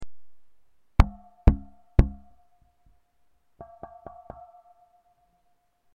Při hraní přes kombo je slyšet něco jako mírná ozvěna.
tohle je nejlepší, co zvládnu (i tak je to dost potichu a budou asi nutný sluchátka) a v tý ,,nahrávce" je na začátku slap na tlumený struně a pak (tam je to asi líp slyšet) při poklepání na snímače a přibližně tak je to slyšet pořád při hraní a poměrně nahlas